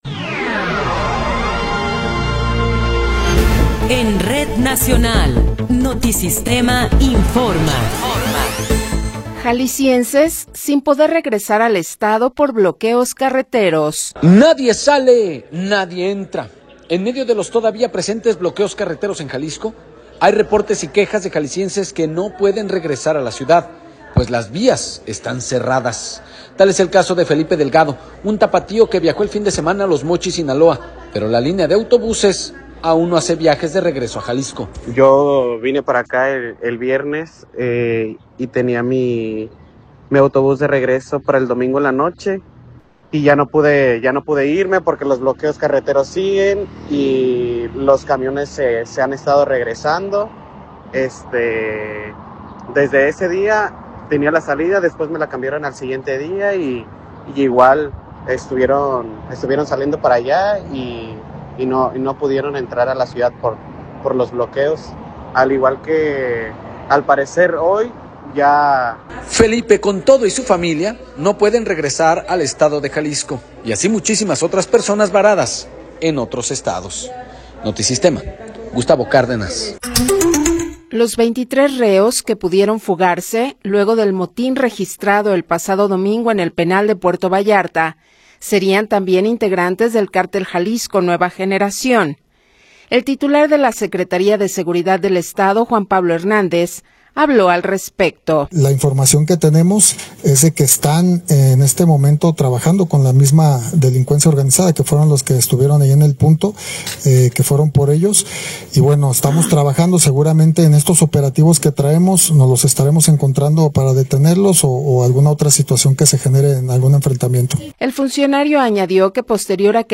Noticiero 15 hrs. – 24 de Febrero de 2026
Resumen informativo Notisistema, la mejor y más completa información cada hora en la hora.